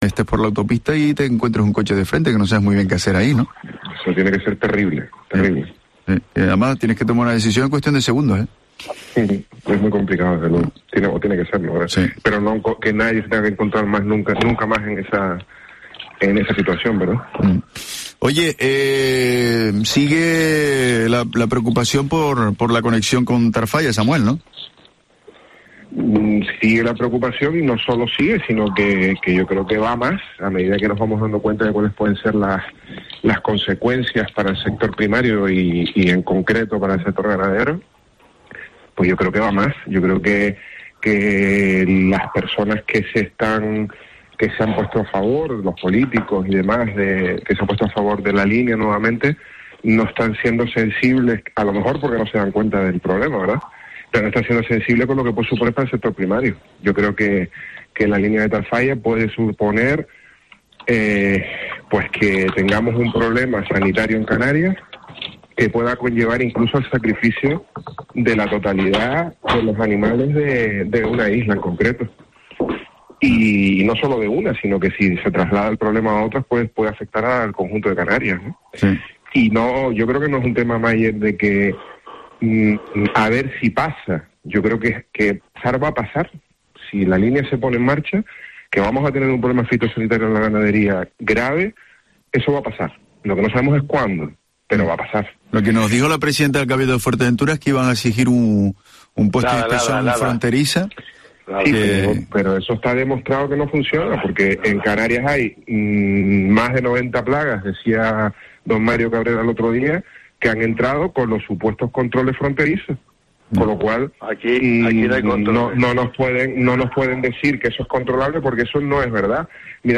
Tertulia Sector Primario en Herrera en COPE Canarias